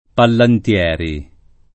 [ pallant L$ ri ]